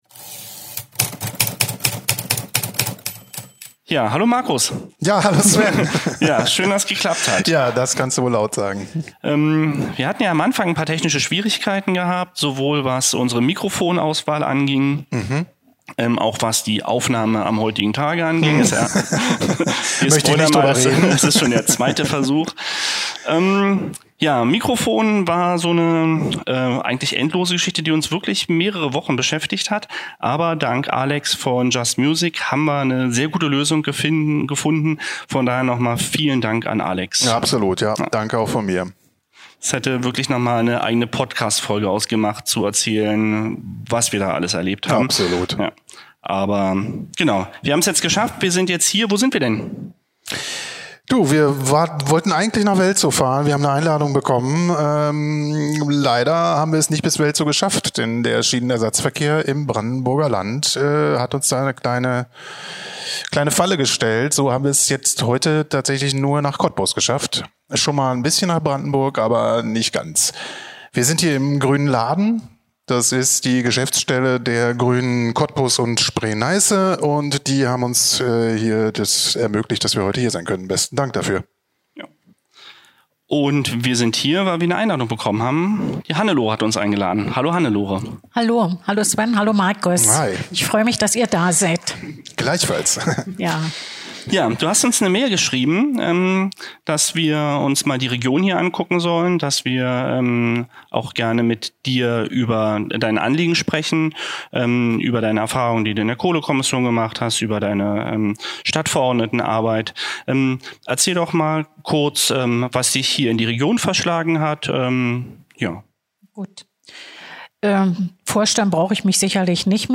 Aber - genau wie es der eigentliche Anspruch der Kohlekommission war - geht es nicht nur darum, etwas zu verhindern, sondern vorallem darum, der Region eine Zukunft zu geben. Wie diese Zukunft aussehen könnte, darüber sprechen wir in einem sehr kurzweiligen und persönlichen Gespräch mit ihr.